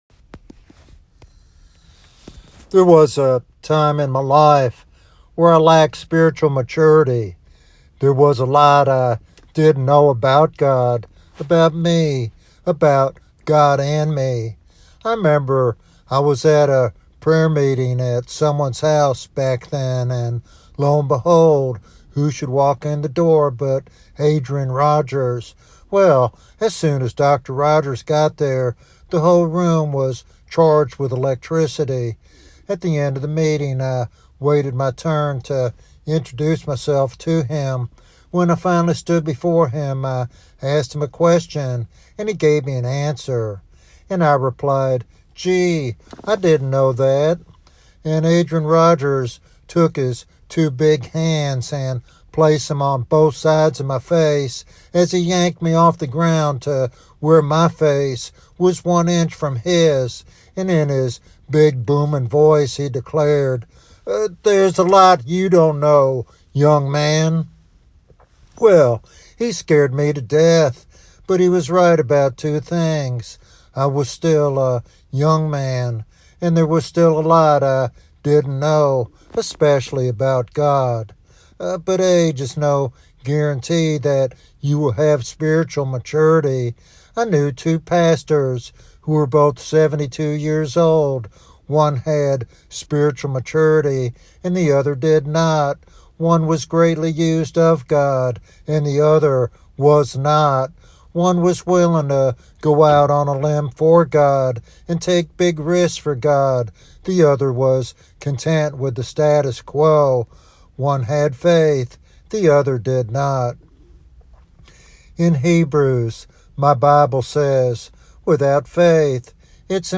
This sermon serves as an inspiring call to deepen one’s trust in God and live a faith-filled life.